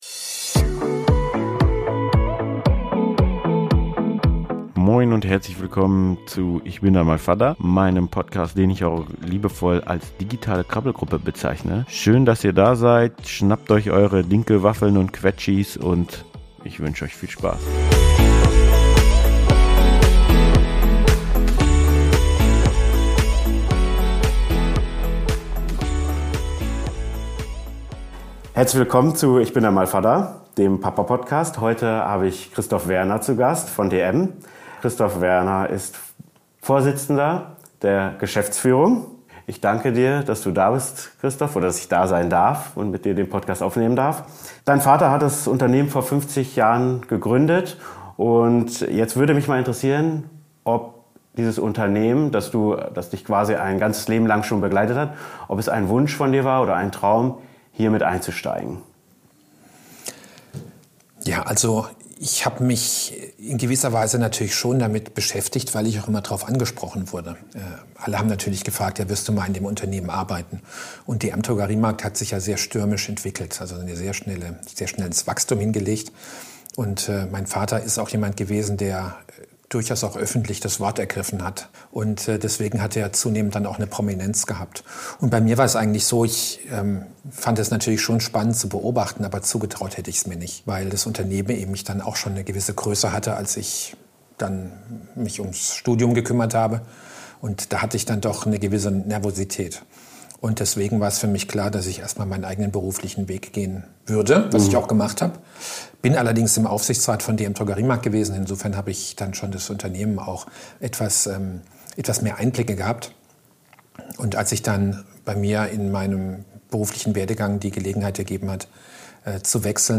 Es ist ein Gespräch voller persönlicher Geschichten, wertvoller Erfahrungen und ehrlicher Einblicke in das Familienleben eines Mannes, der nicht nur eine große Verantwortung in der Wirtschaft trägt, sondern auch als Vater seine ganz eigenen Herausforderungen kennt.